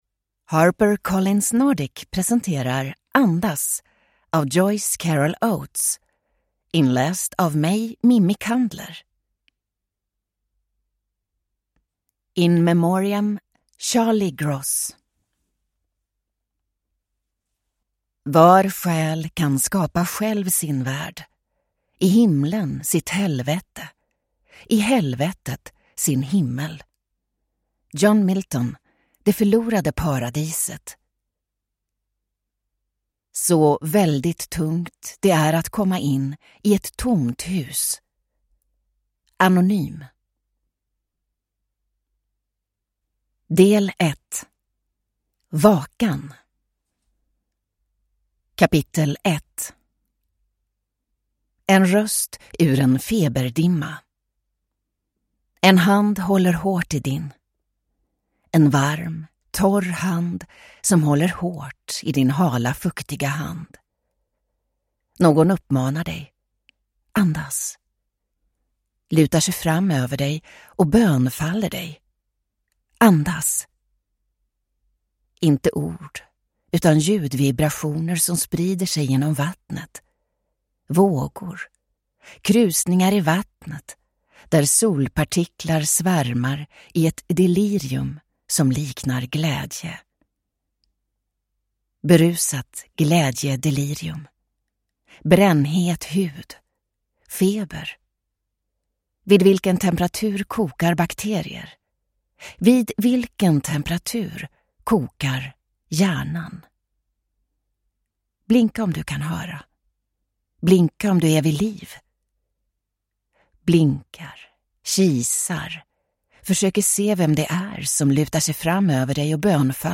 Andas (ljudbok) av Joyce Carol Oates